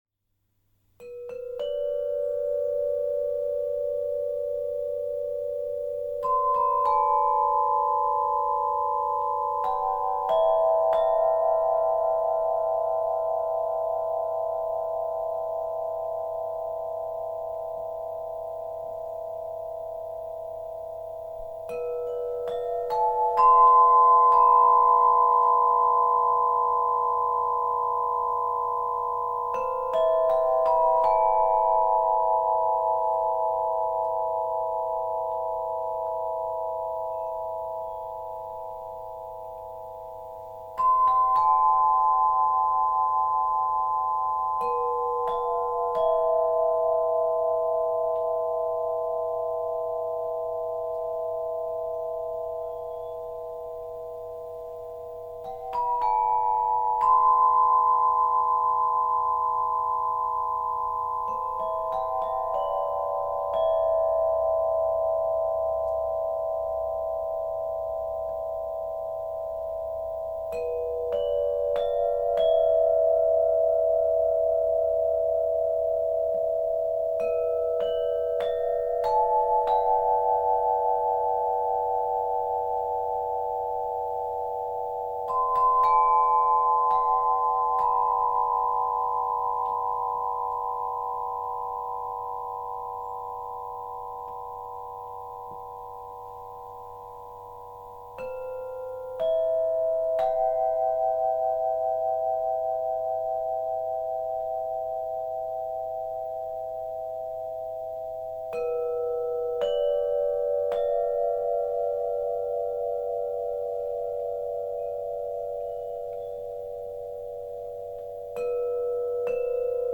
Tubalophone • 7 Chakras
• Inspiré des 7 chakras, avec ses fréquences profondes et apaisantes
• Harmoniques riches et vibrantes, idéales pour la relaxation et la sonothérapie